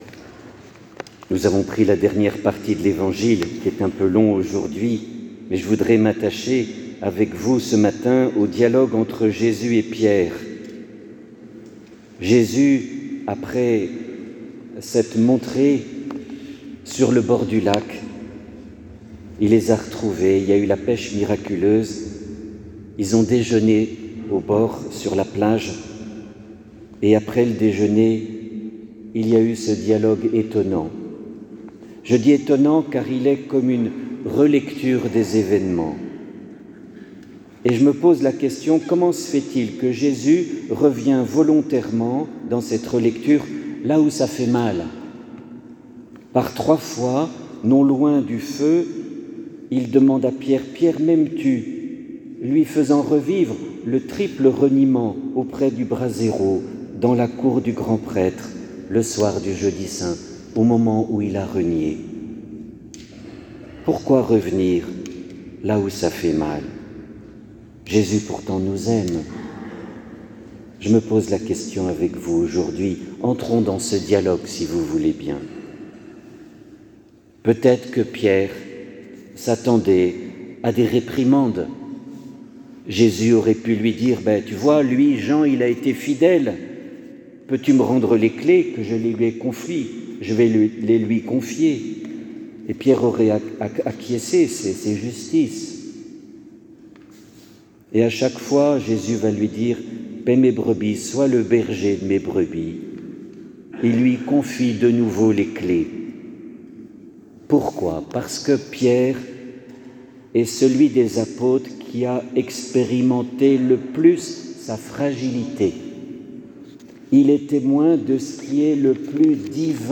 Homélie du 4 mai 2025 : « Pierre, m’aimes tu? »